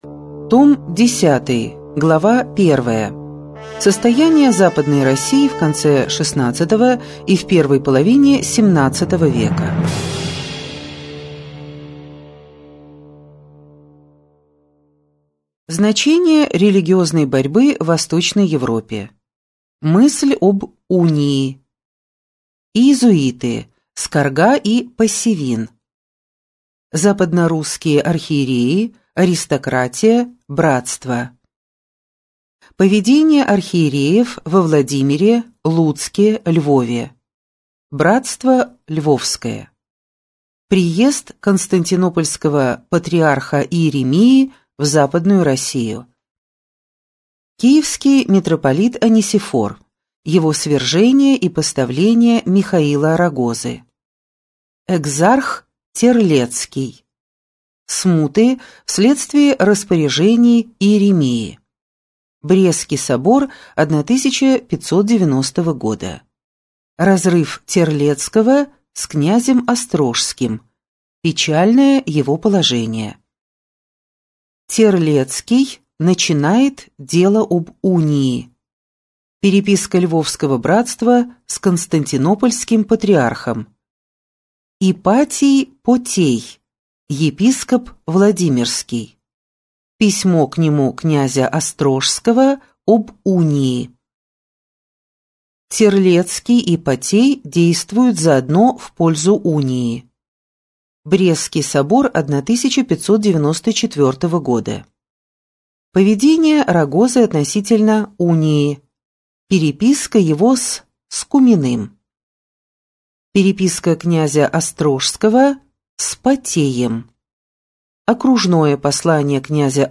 Аудиокнига История России с древнейших времен. Том 10 | Библиотека аудиокниг